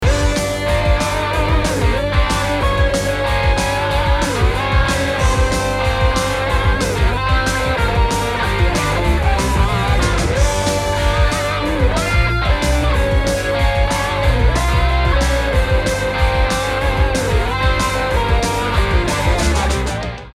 rock instrumental à la guitare
Guitare électrique
Batterie
Basse